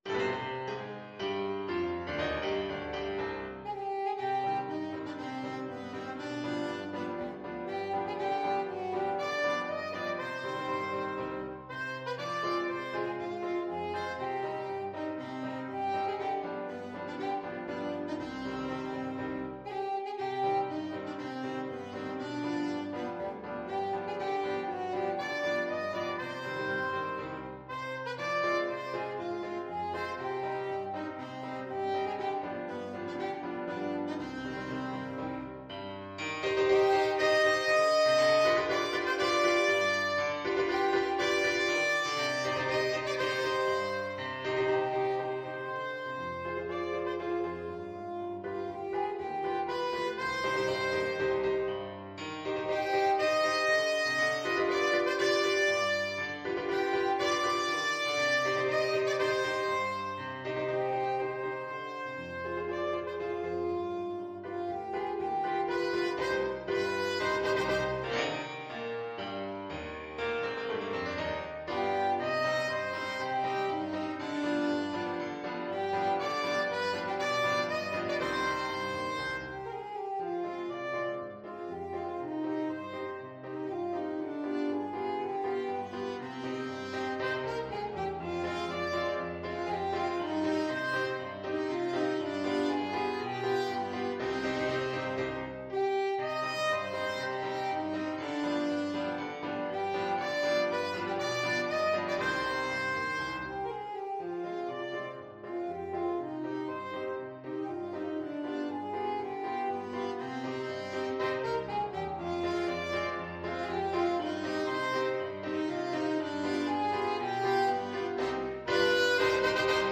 Alto Saxophone version
Alto Saxophone
Allegro = c.120 (View more music marked Allegro)
2/2 (View more 2/2 Music)
Classical (View more Classical Saxophone Music)